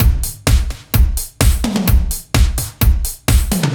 Index of /musicradar/french-house-chillout-samples/128bpm/Beats
FHC_BeatB_128-03.wav